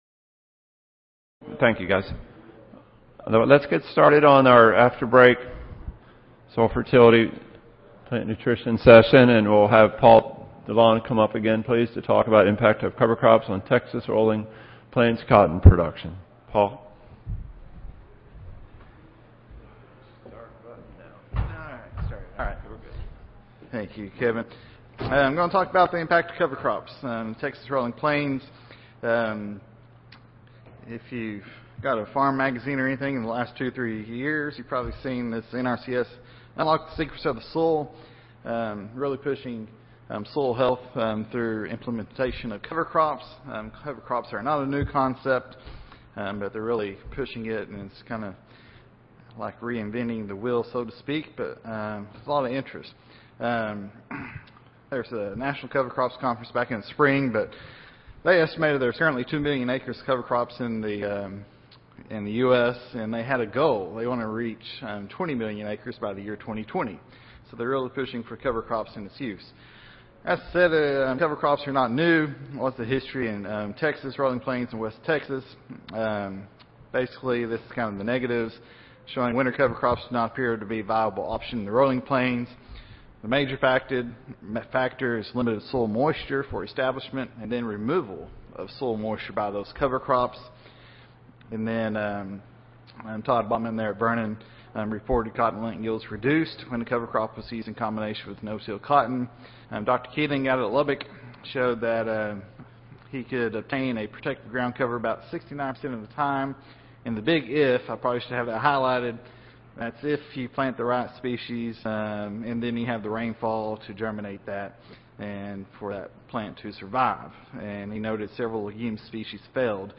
Texas A&M AgriLife Research Audio File Recorded Presentation Interest in cover crops has recently grown throughout the nation.